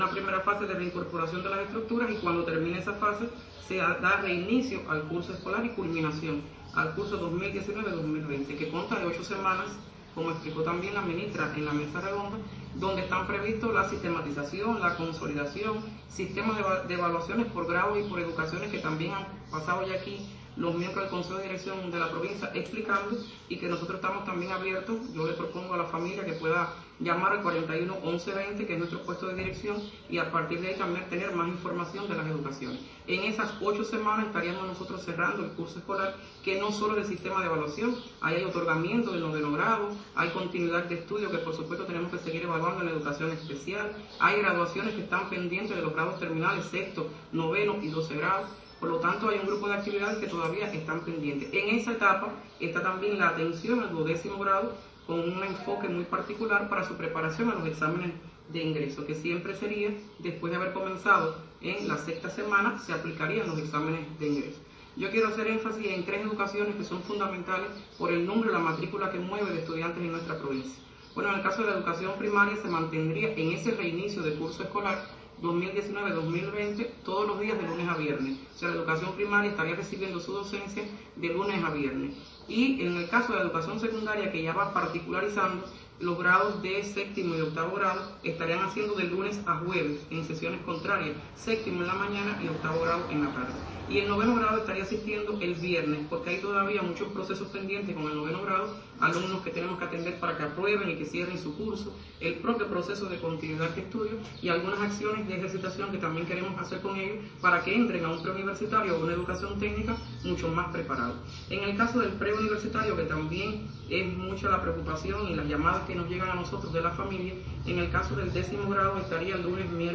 De acuerdo con la información ofrecida por Teresa Pérez Trinchet, directora de la Dirección provincial de Educación, en el programa televisivo Frecuencia 12, se asegura la organización escolar en vista al aislamiento social.